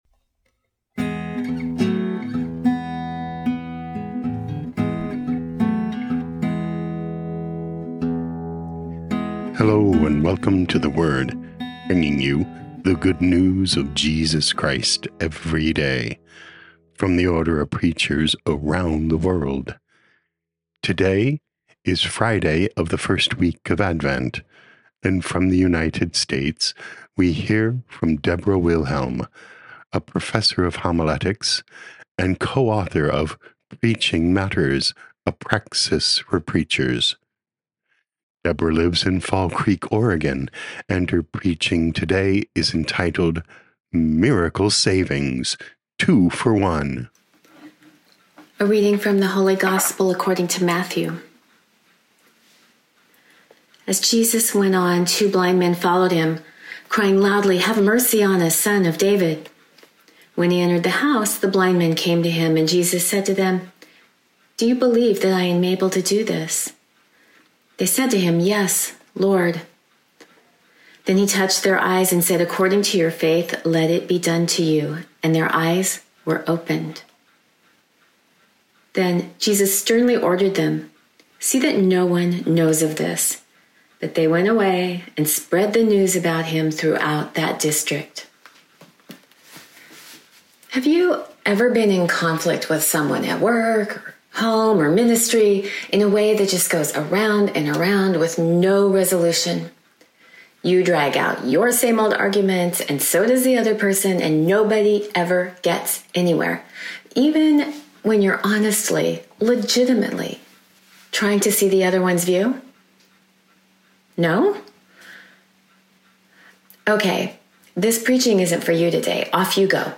6 Dec 2024 Miracle Savings: Two for One Podcast: Play in new window | Download For 6 December 2024, Friday of the 1st week of Advent, based on Matthew 9:27-31, sent in from Fall Creek, Oregon, USA.
Preaching